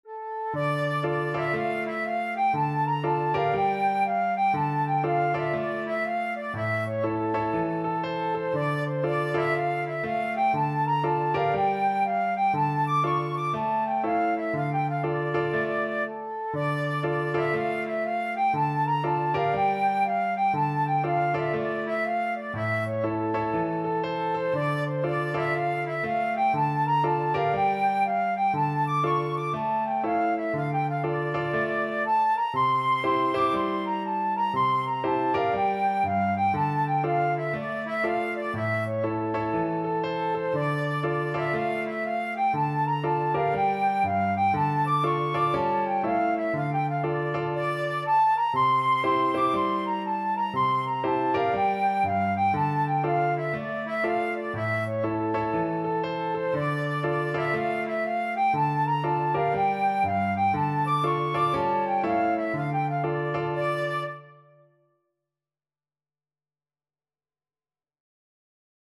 Flute
2/2 (View more 2/2 Music)
With a swing = c.60
D minor (Sounding Pitch) (View more D minor Music for Flute )
Traditional (View more Traditional Flute Music)